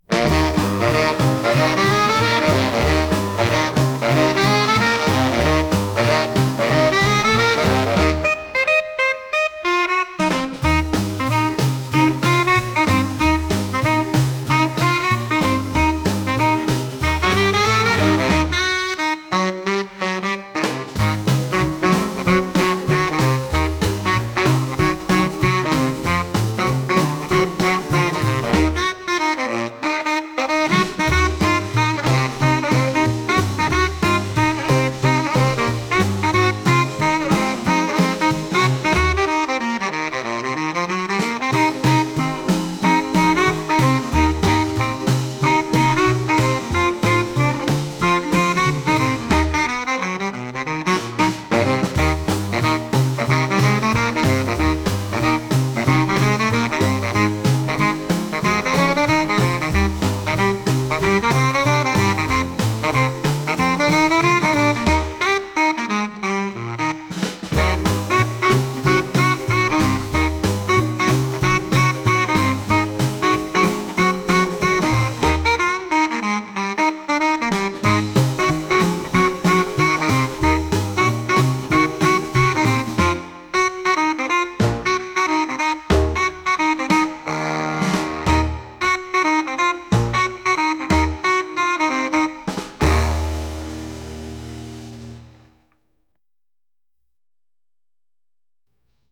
陽気になりたいような音楽です。